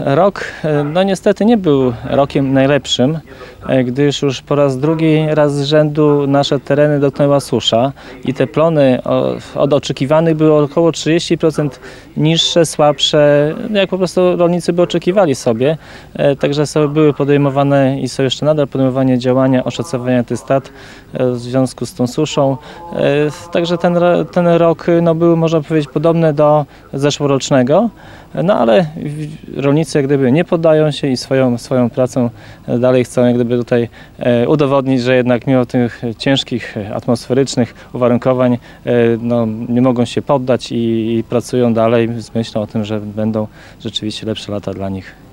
Dożynki Parafialne odbywają się w niedzielę (25.08) w pokamedulskim klasztorze nad Wigrami.
– Czas podziękować rolnikom za ich ciężką pracę, szczególnie w tym trudnym, spowodowanym suszą roku – Zbigniew Mackiewicz, wójt gminy Suwałki.